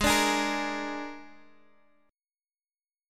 Absus2b5 chord